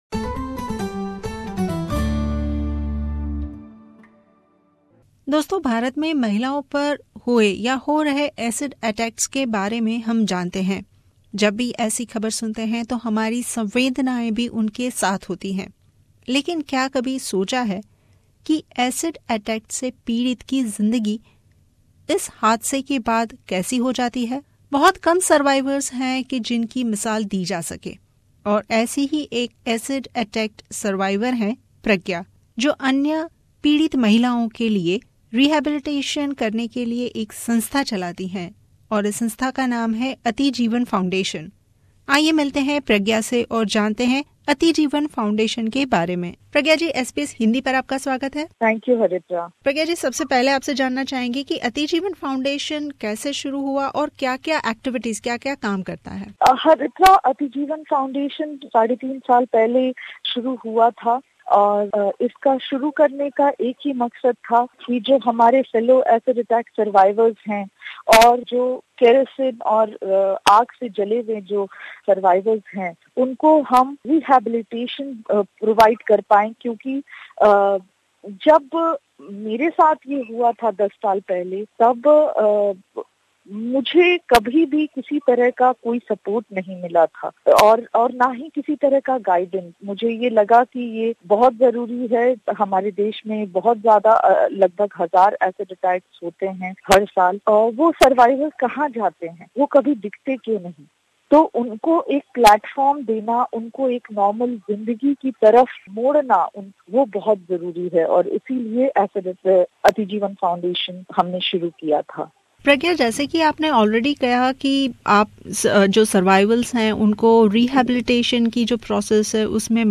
विशेष भेंटवार्ता